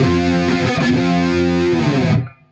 Index of /musicradar/80s-heat-samples/95bpm
AM_HeroGuitar_95-F02.wav